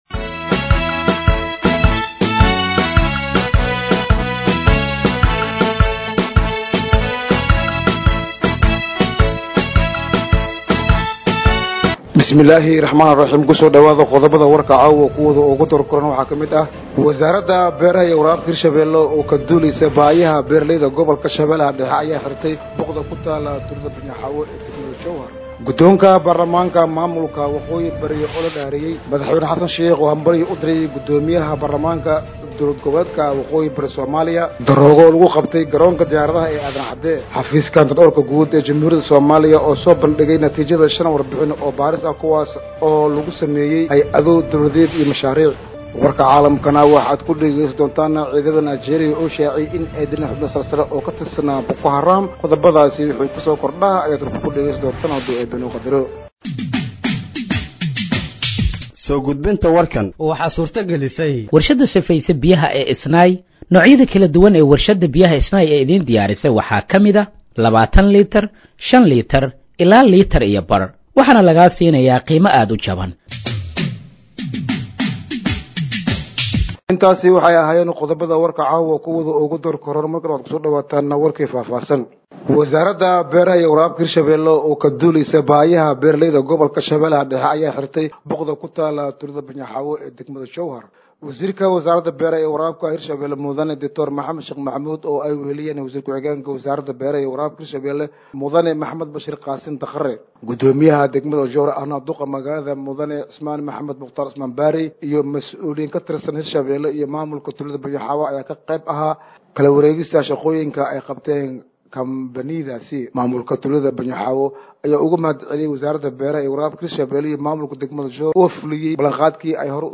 Dhageeyso Warka Habeenimo ee Radiojowhar 23/08/2025